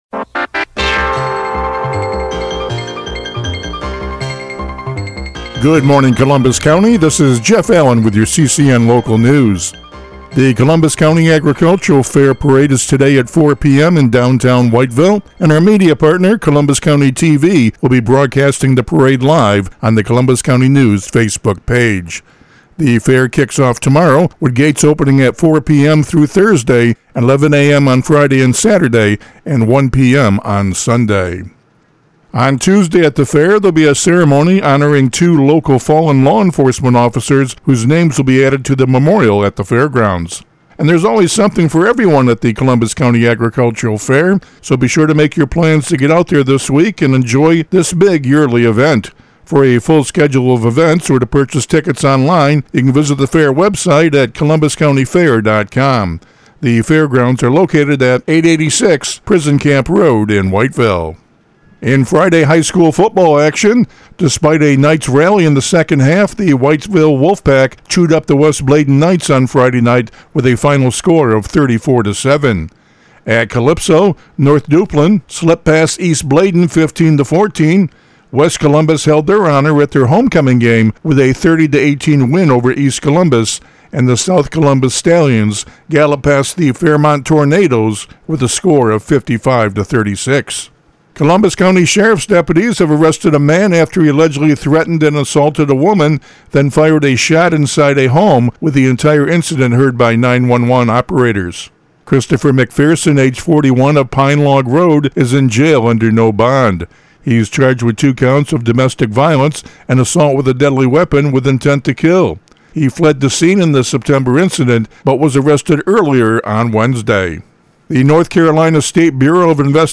CCN Radio News — Morning Report for October 6, 2025